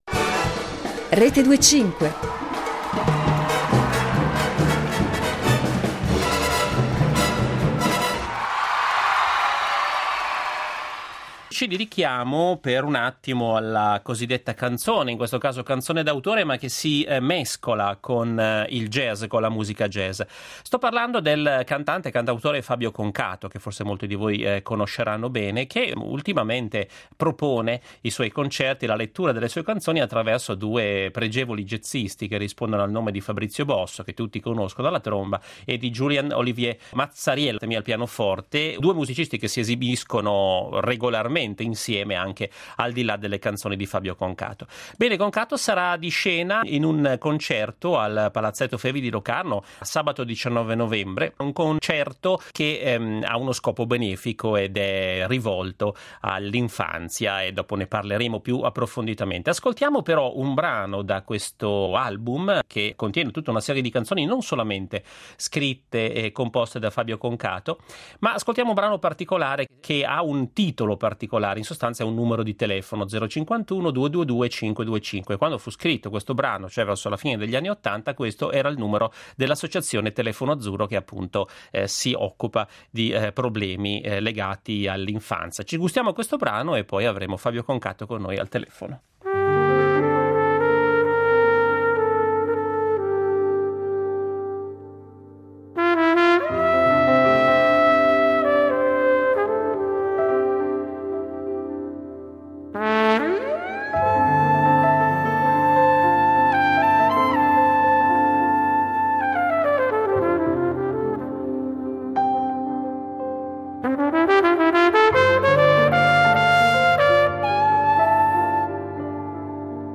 Incontro con Fabio Concato